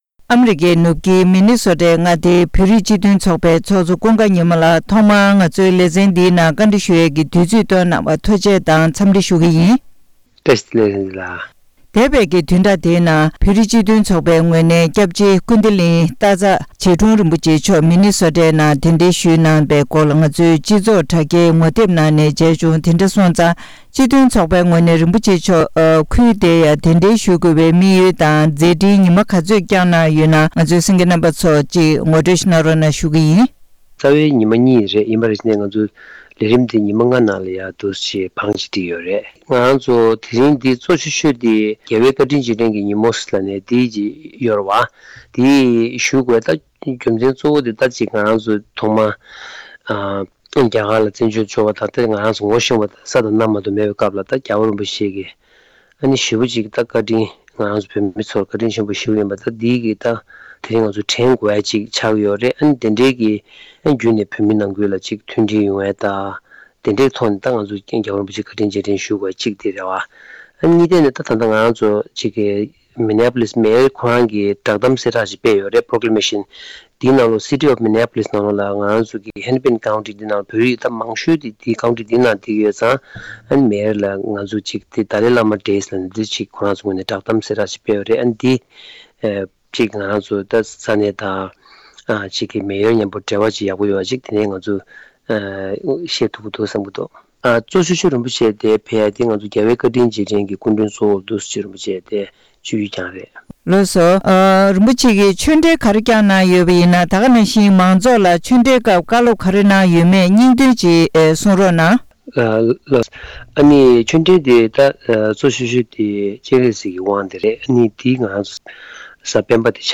སྒྲ་ལྡན་གསར་འགྱུར།
བཀའ་འདྲི་ཞུས་པ་ཞིག་སྙན་སྒྲོན་ཞུས་གནང་གི་རེད།